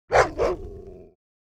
mixkit-hellhound-monster-attack-dog-wolf-creature-3015.wav